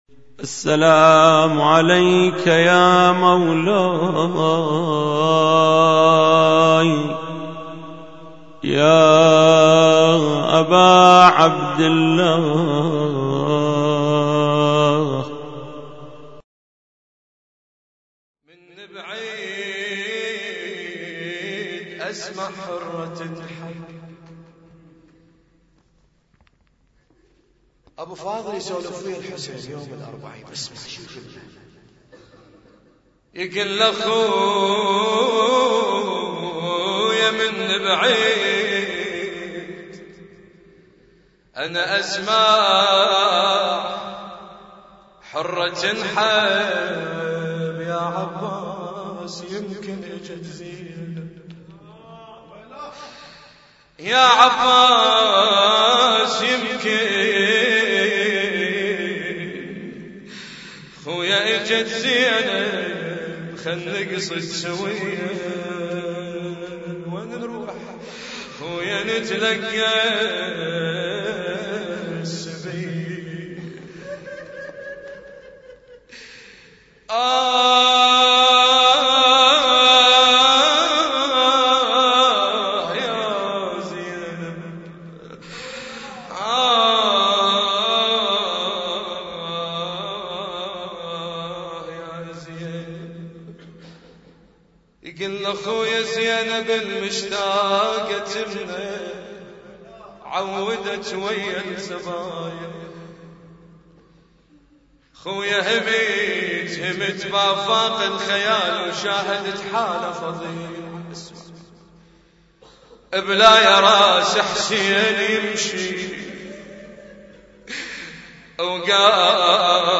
اسم التصنيف: المـكتبة الصــوتيه >> الصوتيات المتنوعة >> النواعي